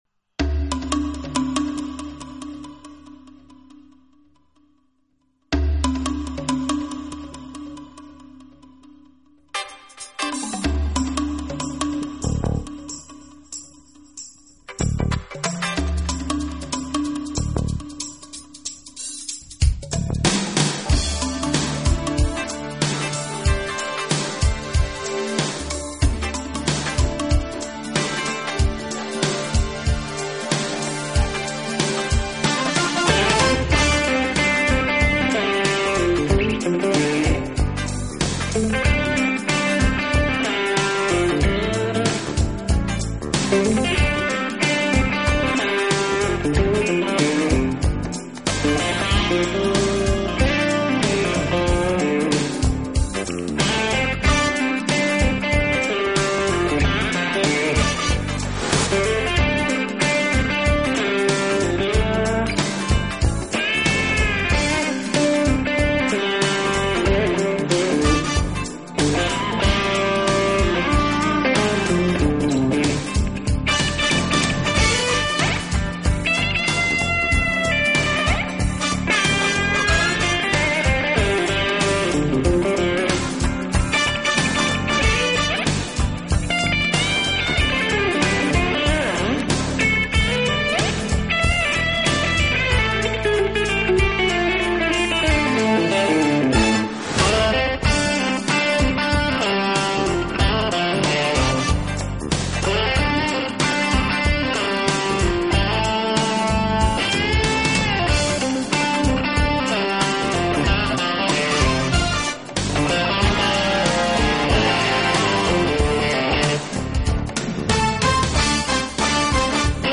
资源类型: Acoustic guitar